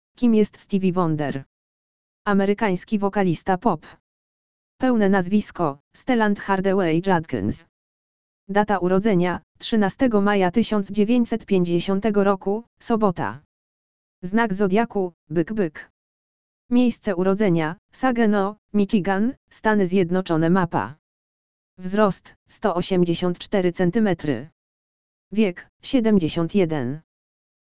AUDIO LEKTOR URODZINY STEVIE WONDER
audio_lektor_urodziny_stevie_wonder.mp3